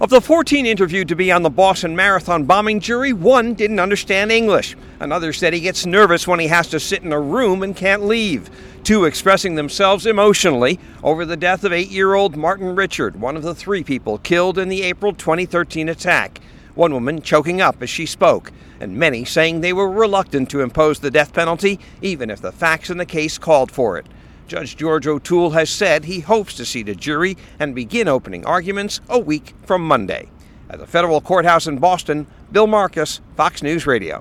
HAS MORE FROM THE FEDERAL COURTHOUSE IN BOSTON.